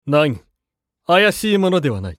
男性
厨二病ボイス～戦闘ボイス～
【アイテム使用2】